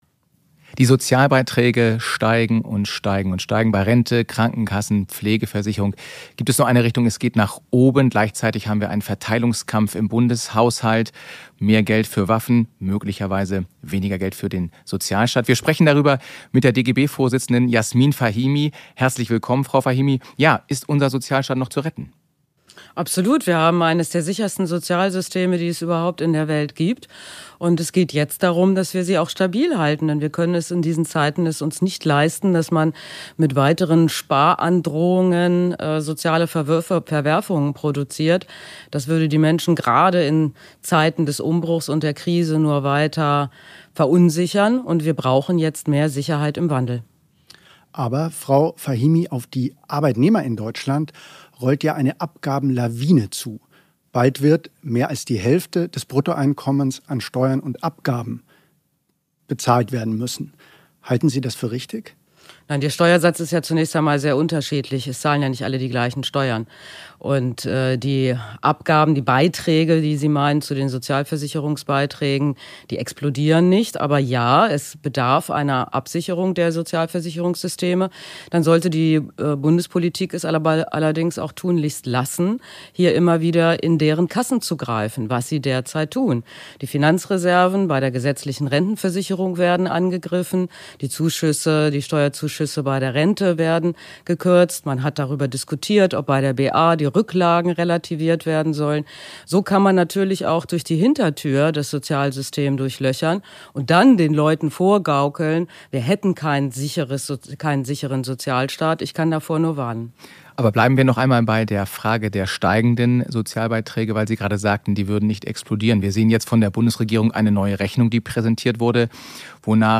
DGB-Chefin Fahimi im BILD-Interview